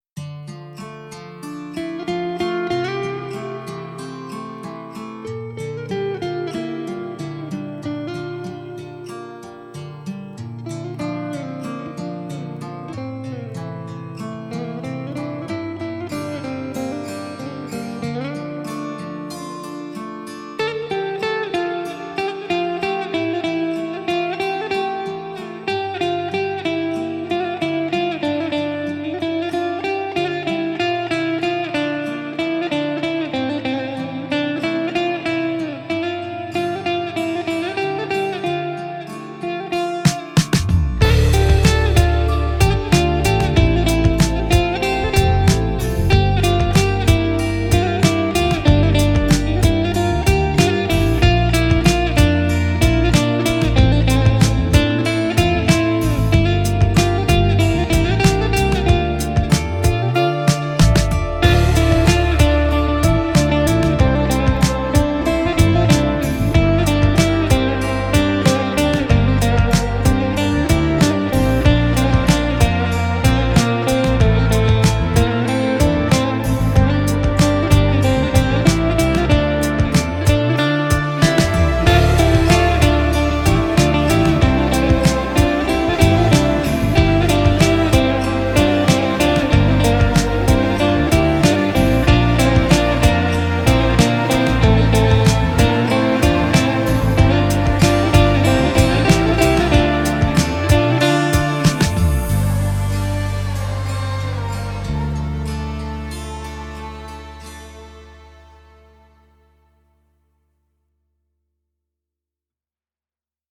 tema dizi müziği, duygusal huzurlu rahatlatıcı fon müziği.